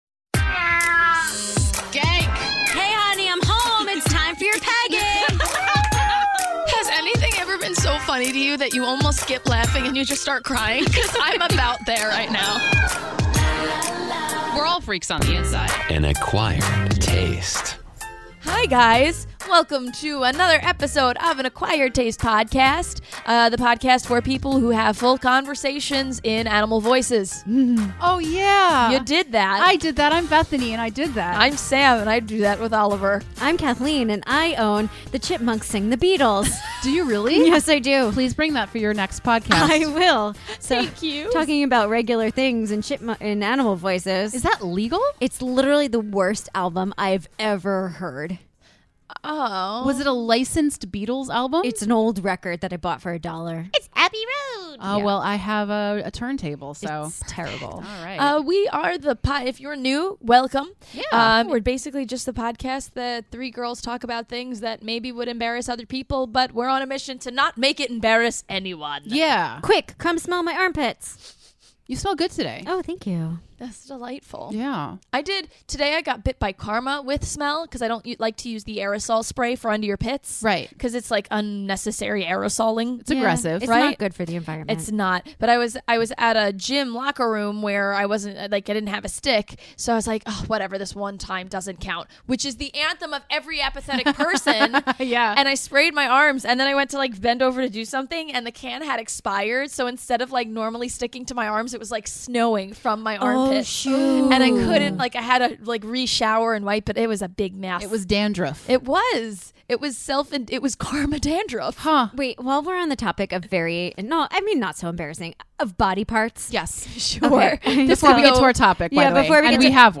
the women try to pull apart what constitutes actual cheating and what’s harmless fun!